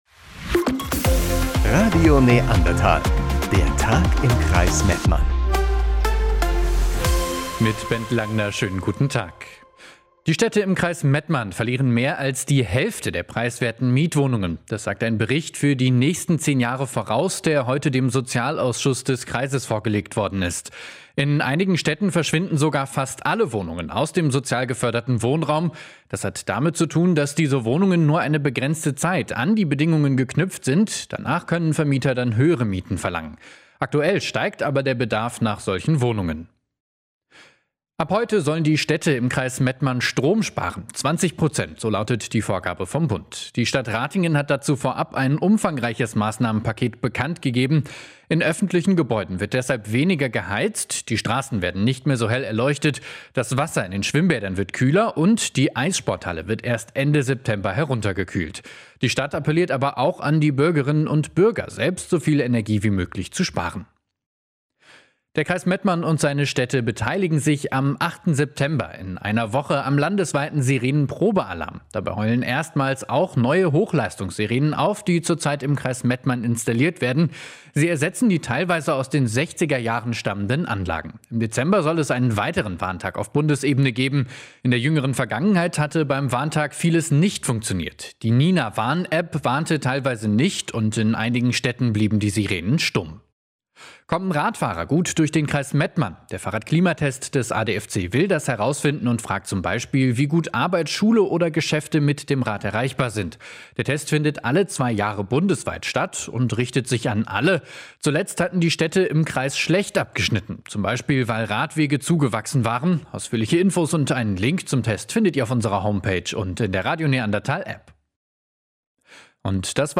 Die wichtigsten Nachrichten des Tages aus dem Kreis Mettmann.